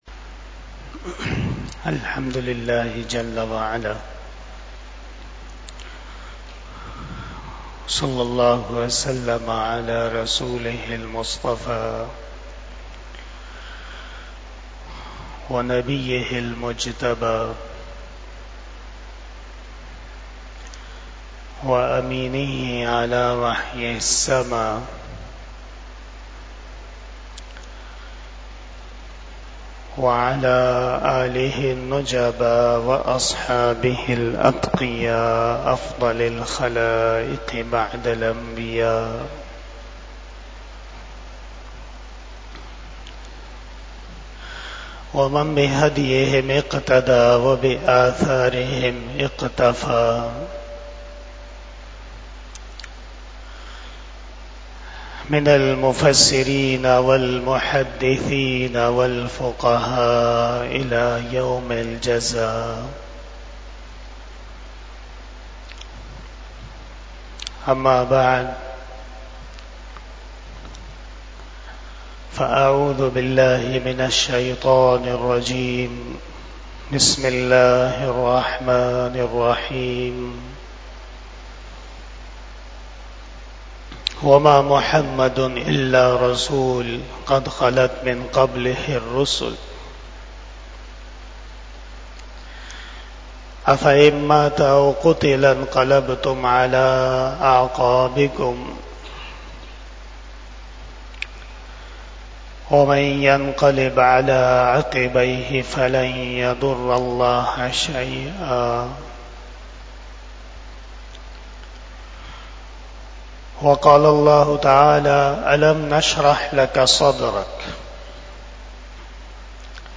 37 Bayan E Jummah 13 September 2024 (09 Rabi Ul Awwal 1446 HJ)